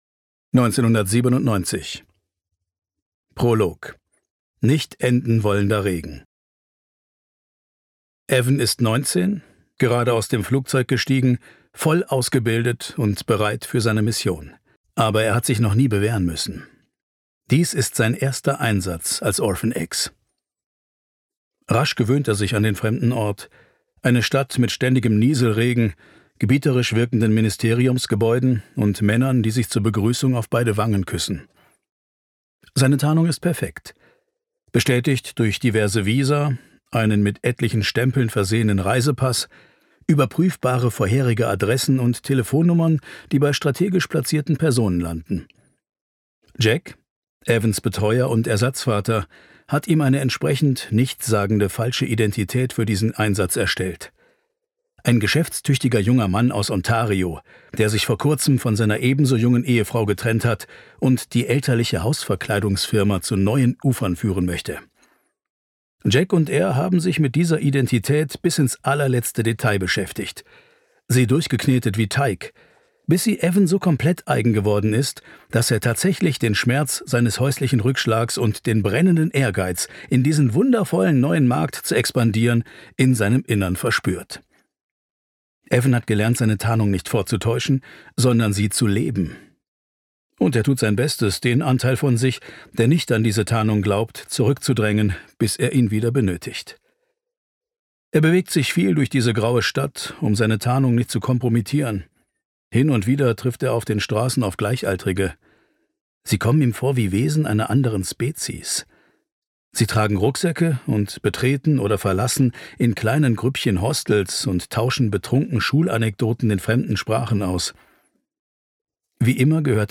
2019 | Originalfassung, ungekürzt